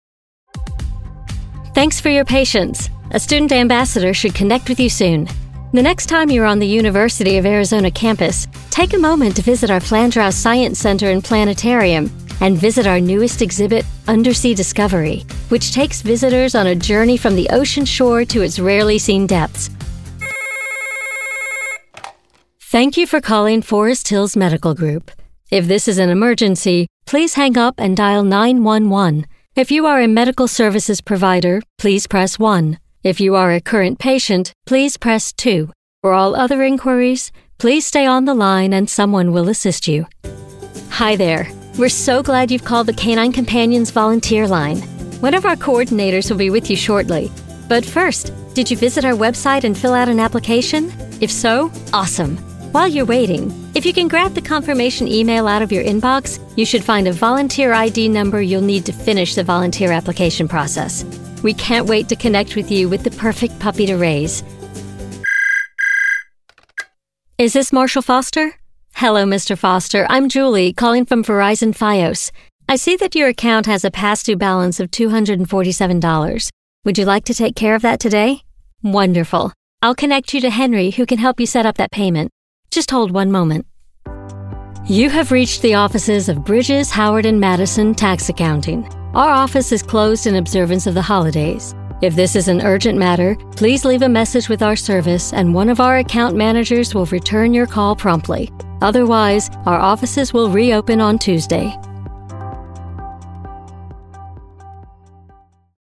English (American)
Natural, Accessible, Versatile, Friendly, Corporate
Telephony